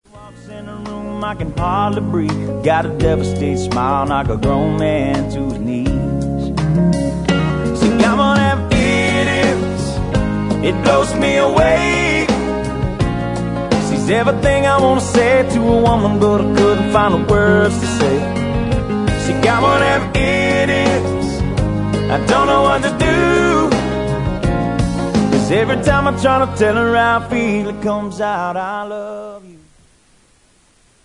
• Southern Rock